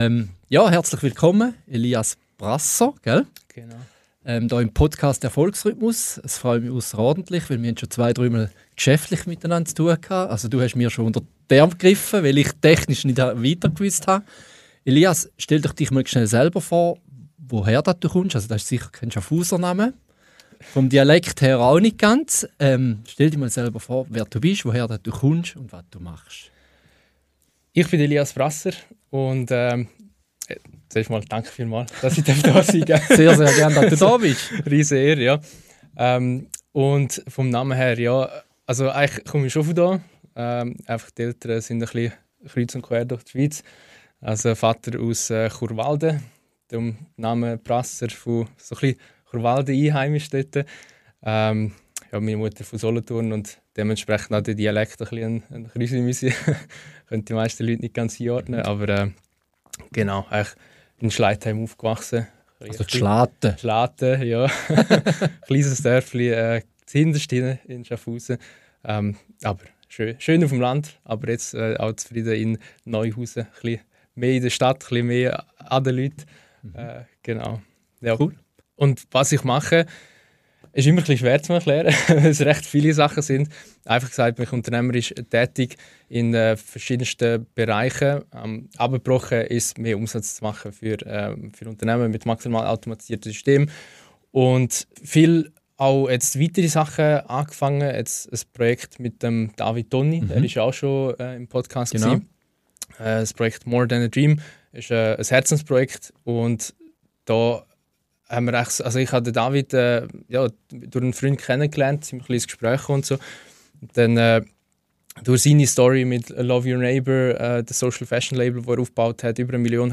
Ein inspirierendes Gespräch über Mut, Umsetzung und die Kraft, das eigene Leben bewusst zu gestalten.